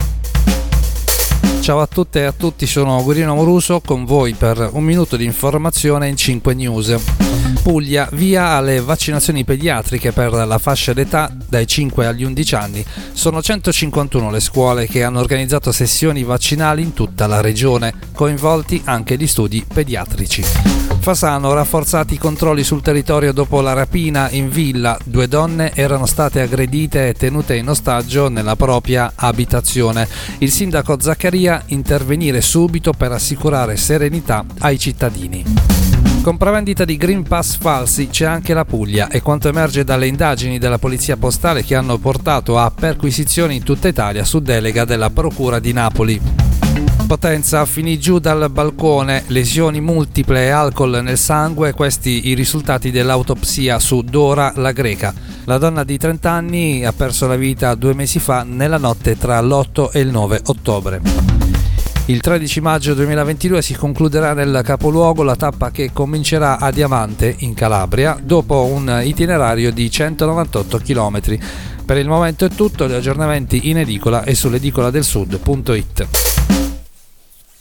Giornale radio alle ore 19.